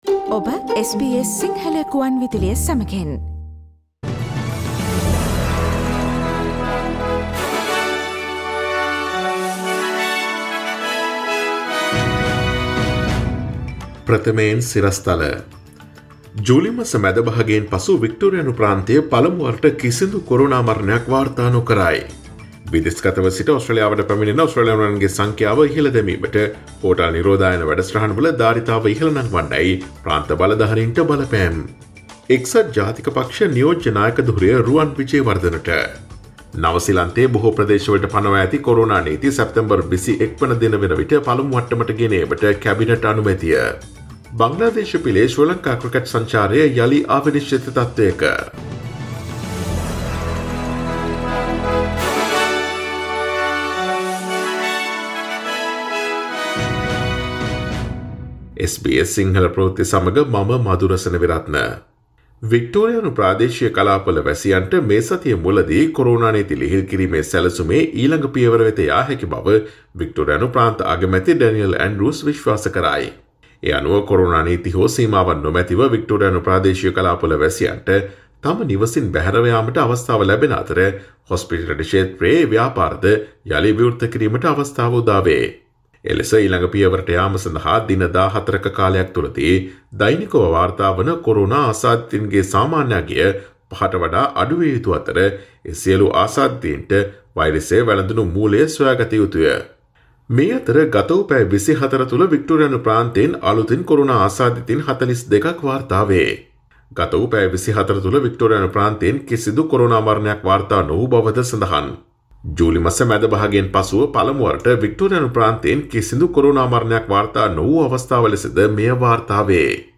Daily News bulletin of SBS Sinhala Service: Tuesday 15 September 2020